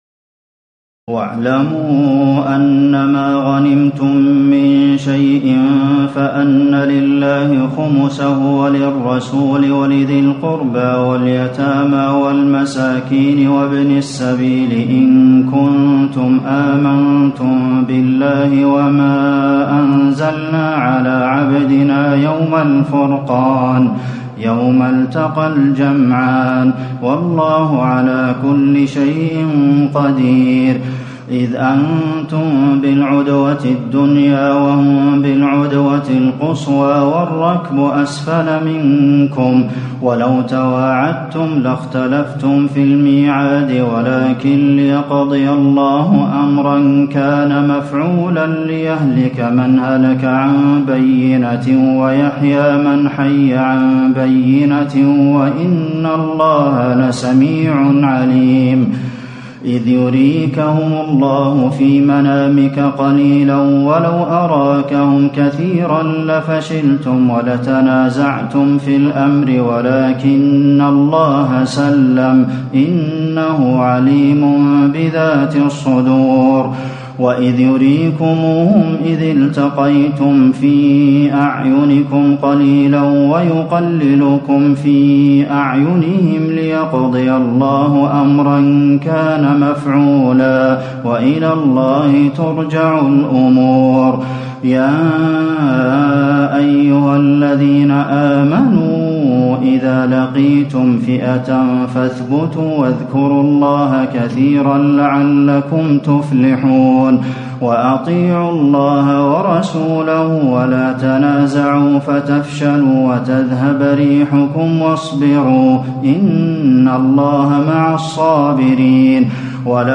تراويح الليلة التاسعة رمضان 1437هـ من سورتي الأنفال (41-75) و التوبة (1-33) Taraweeh 9 st night Ramadan 1437H from Surah Al-Anfal and At-Tawba > تراويح الحرم النبوي عام 1437 🕌 > التراويح - تلاوات الحرمين